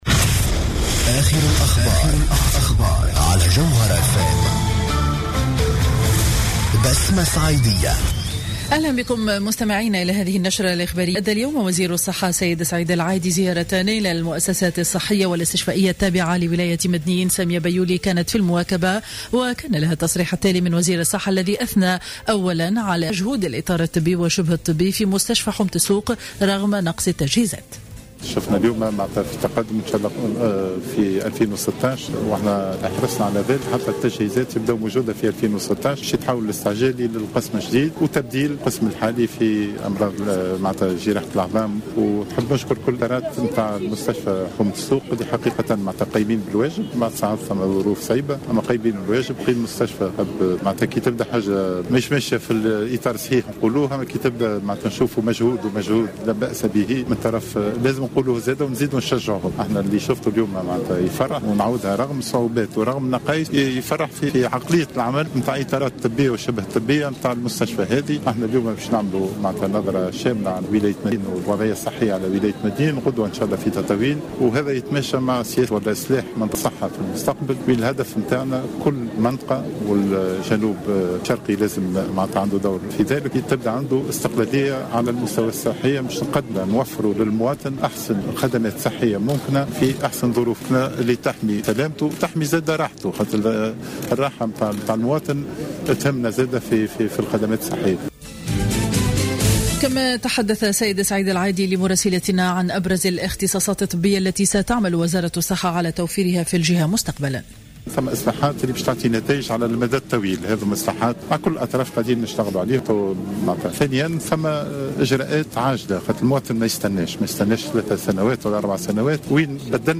نشرة أخبار منتصف النهار ليوم الخميس 10 سبتمبر 2015